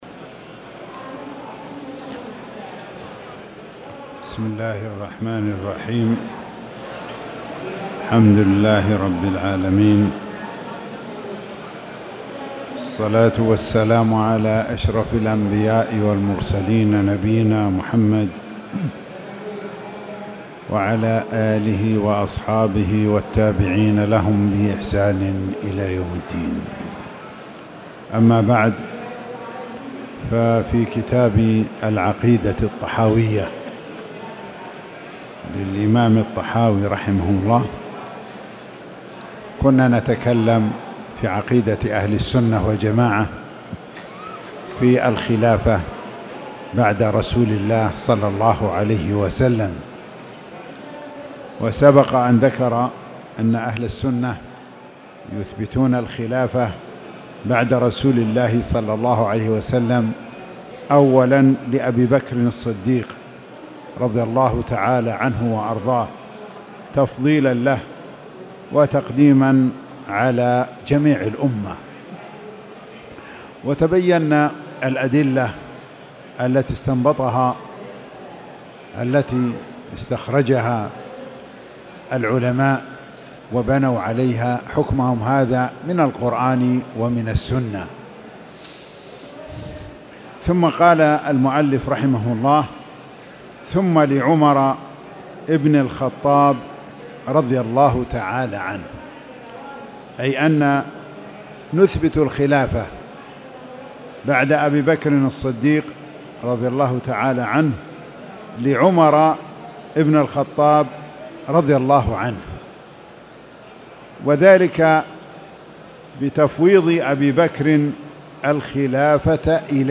تاريخ النشر ٢١ صفر ١٤٣٨ هـ المكان: المسجد الحرام الشيخ